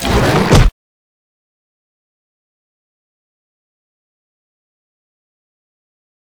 vpunch2.wav